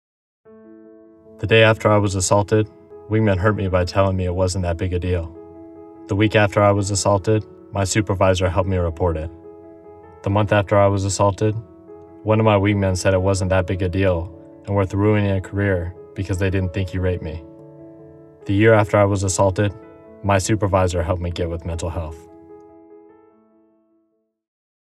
The secretary, chief of staff and chief master sgt. of the Air Force joined other Airmen to read testimonies from sexual assault survivors and discussed Airmen’s roles in supporting sexual assault survivors and preventing this crime.
24 Year Old Male Testimonial